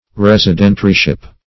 Search Result for " residentiaryship" : The Collaborative International Dictionary of English v.0.48: Residentiaryship \Res`i*den"tia*ry*ship\, n. The office or condition of a residentiary.
residentiaryship.mp3